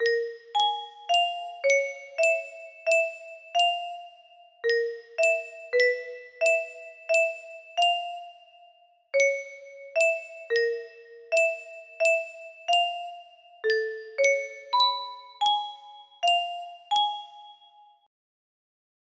made into a music box